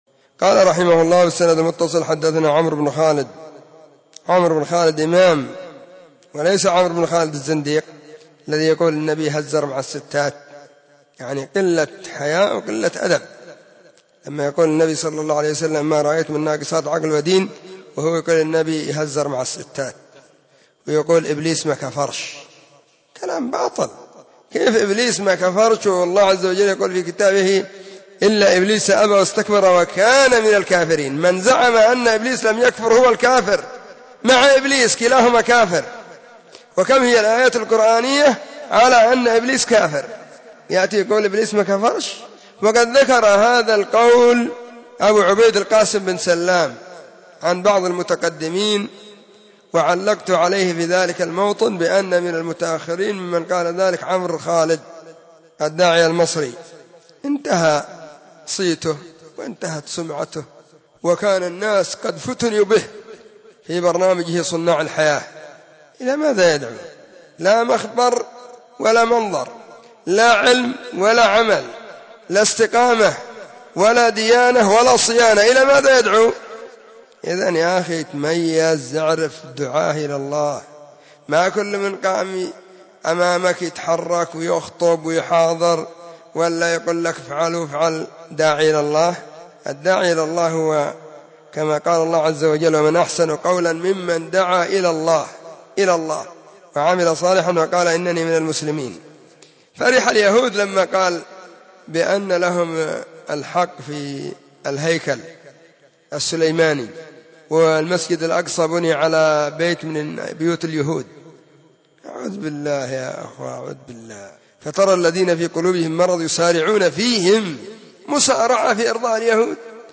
📢 مسجد الصحابة – بالغيضة – المهرة – اليمن حرسها الله.